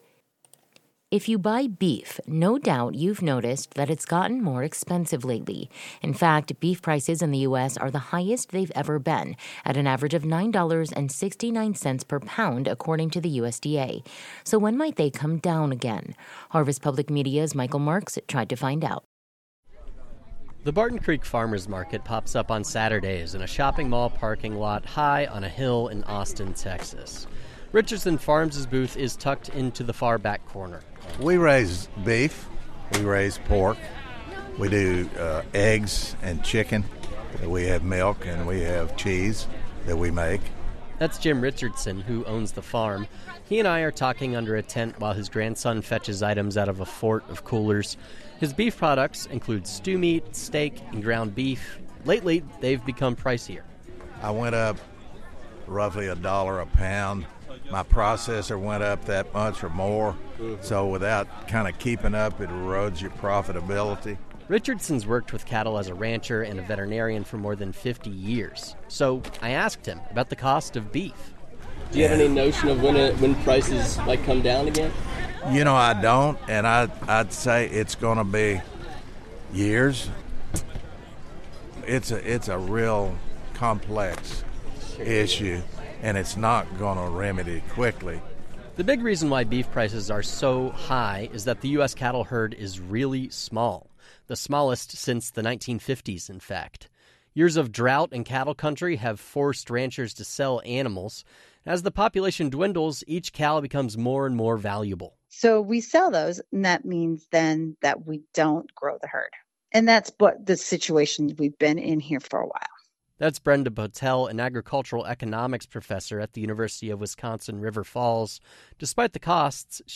When will beef prices drop? We asked a rancher, a butcher and an economist